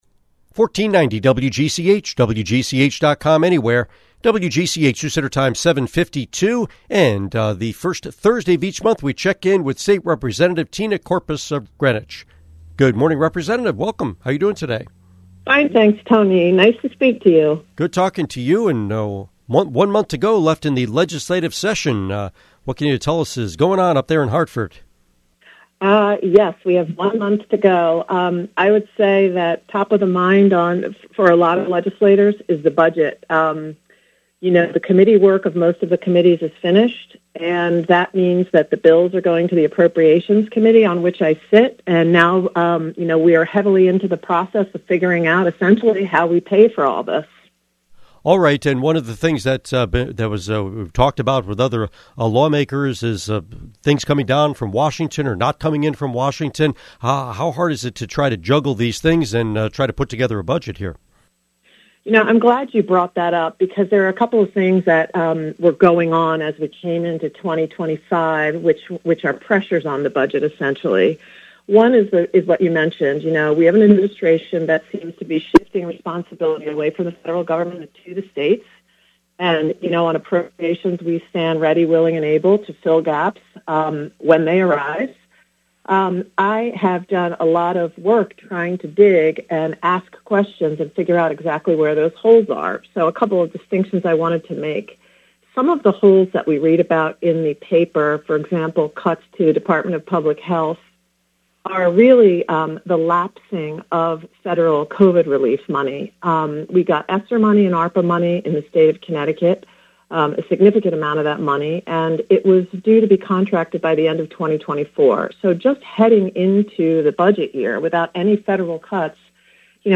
Interview with State Representative Tina Courpas